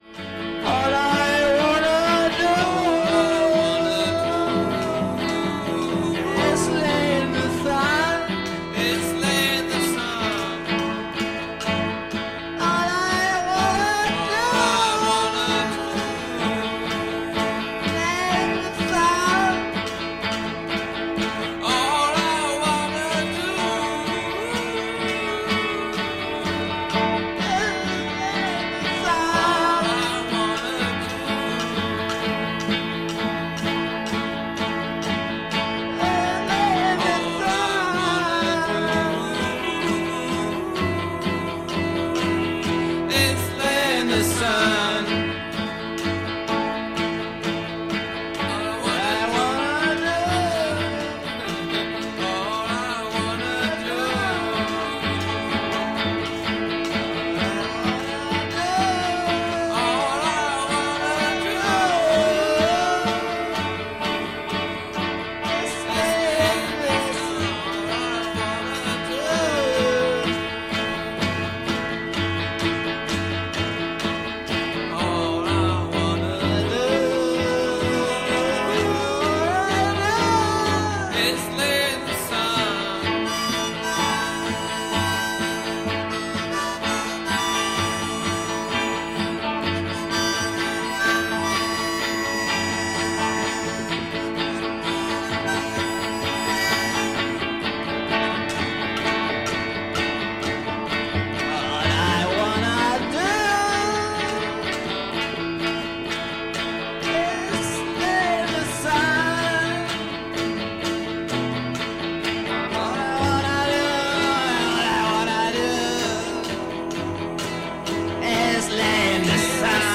Again, without rehearsals or repeats.
All three are non-educated and non-professional musicians.